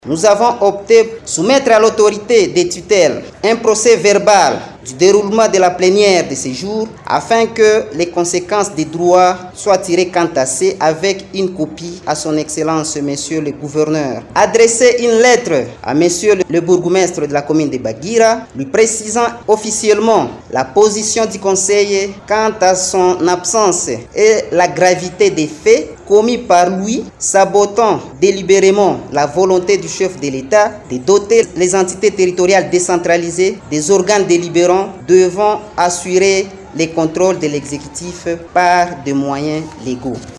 Ceci a été communiqué au cours d’un point de presse tenu dans l’après-midi de lundi 21 octobre après avoir constaté l’absence du bourgmestre Patience Bengehya à la plénière du conseil communal alors qu’il était régulièrement invité.
Dans sa communication, le conseil communal de Bagira rappelle que ce sera la deuxième fois que l’autorité communale refuse de se présenter pour répondre à cet exercice démocratique en plus d’autres attitudes irrespectueuses à l’égard de l’organe délibérant, a expliqué son rapporteur Christian Chito.